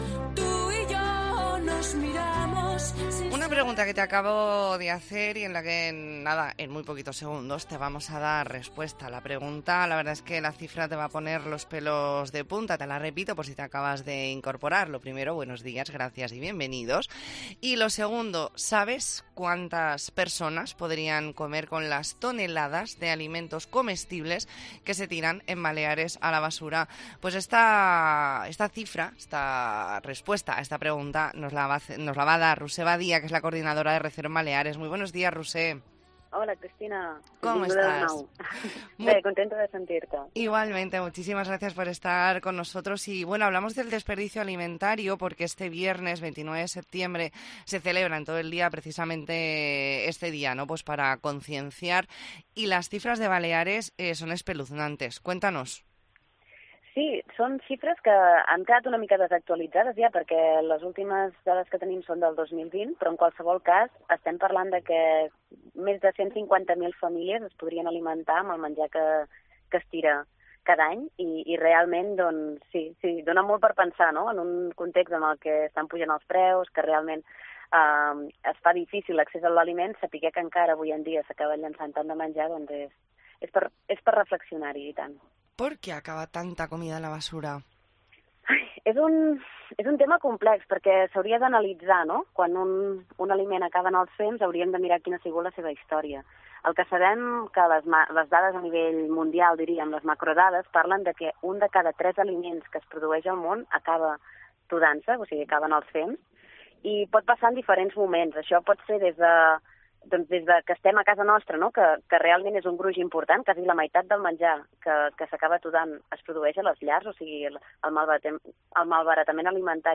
Entrevista en La Mañana en COPE Más Mallorca, lunes 25 de septiembre de 2023.